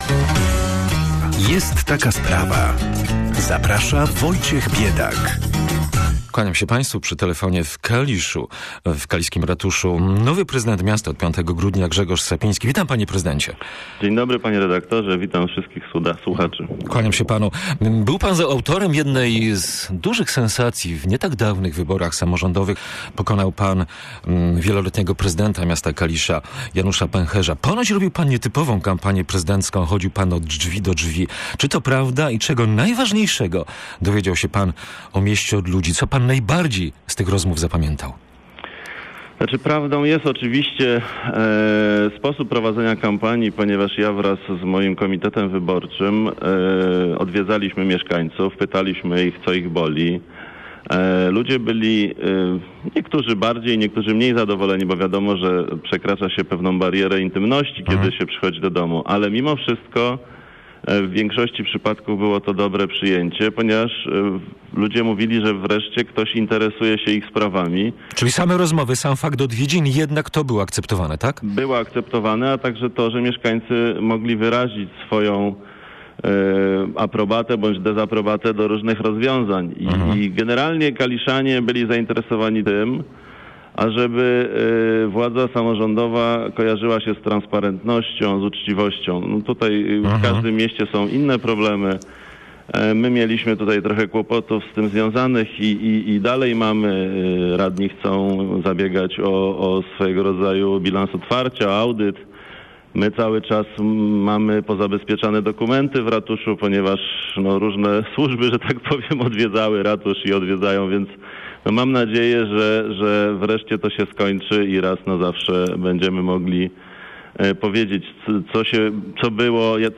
Mam nadzieję, że okres powyborczy, okres porządkowania spraw wkrótce się skończy i będziemy mogli przystąpić do nowego otwarcia dla Kalisza - mówi Grzegorz Sapiński, od 5 grudnia nowy prezydent drugiego po Poznaniu, największego miasta Wielkopolski.